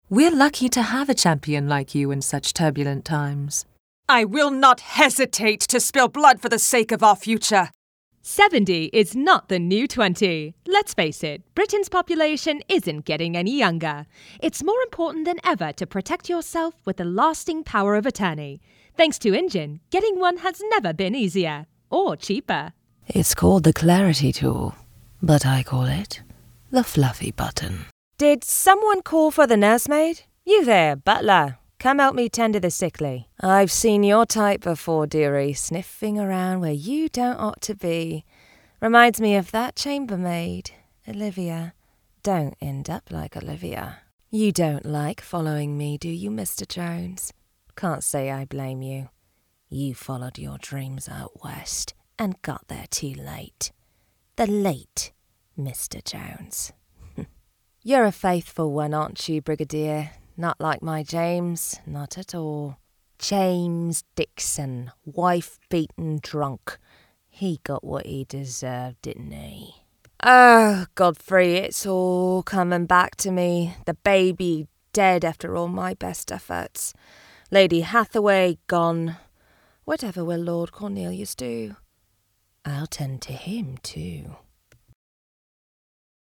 Adult, Young Adult
Has Own Studio
british english
British_Demo.mp3